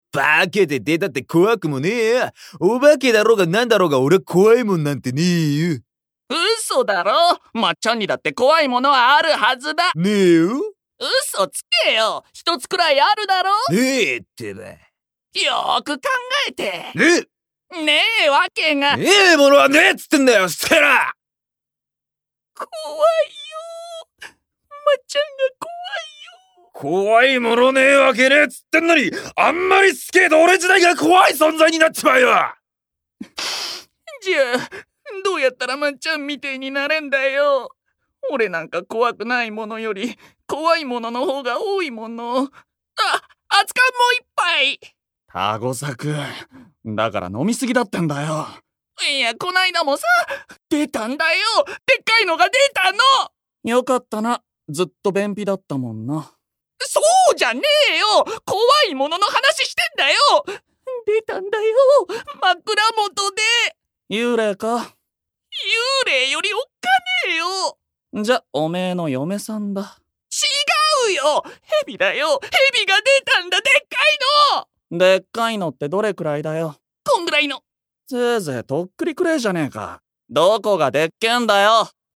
色男×落語で彩るドラマCD『ハンサム落語』シリーズが発売決定！